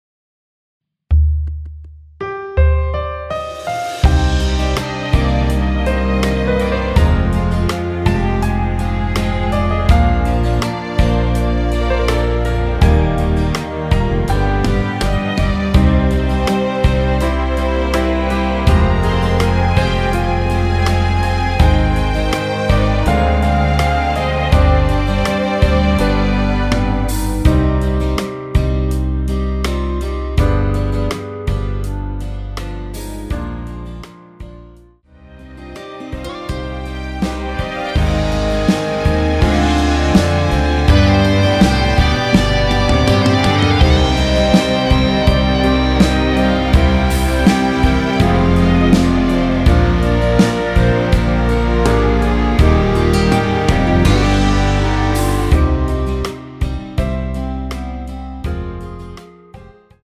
원키에서(-2)내린 멜로디 포함된 MR입니다.
?Cm
앞부분30초, 뒷부분30초씩 편집해서 올려 드리고 있습니다.